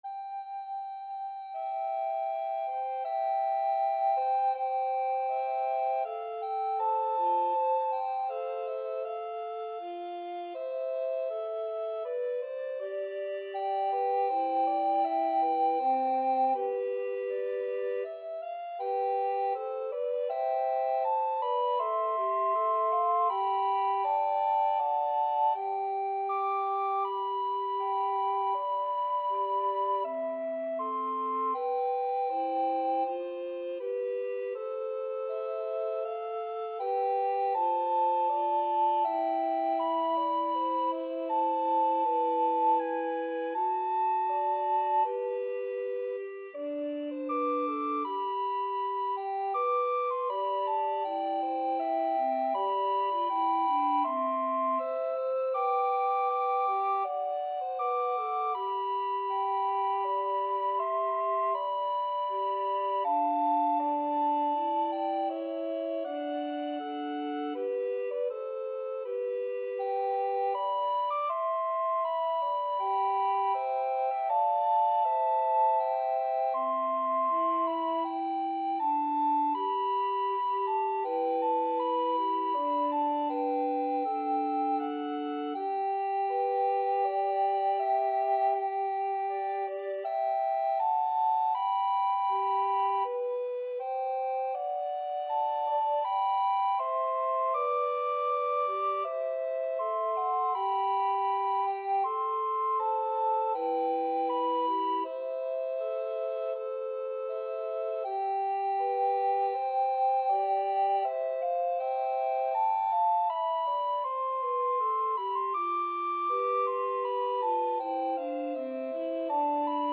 Free Sheet music for Recorder Trio
Soprano RecorderAlto RecorderTenor Recorder
C major (Sounding Pitch) (View more C major Music for Recorder Trio )
4/2 (View more 4/2 Music)
Classical (View more Classical Recorder Trio Music)